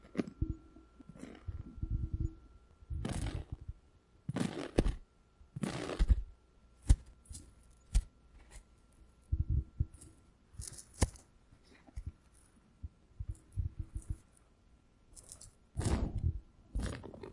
惊悚片/短片之声《内心的孩子》 " 项链
声道立体声